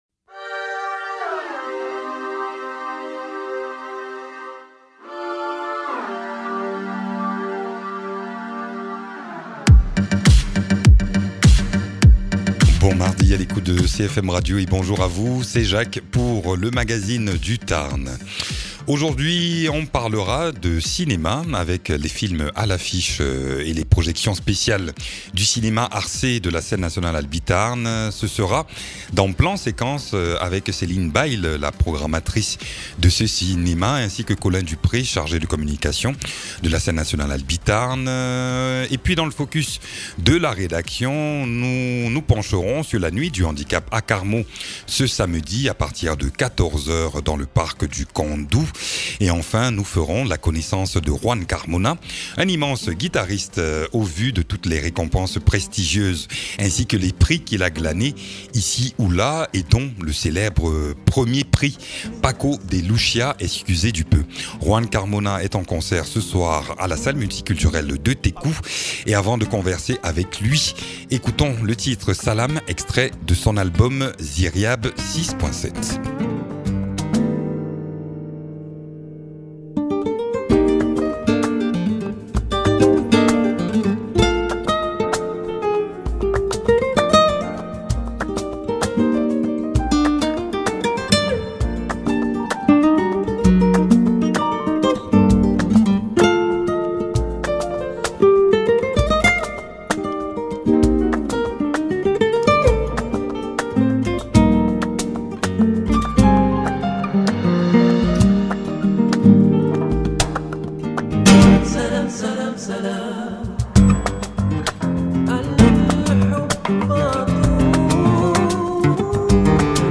Invité(s) : Juan Carmona, guitariste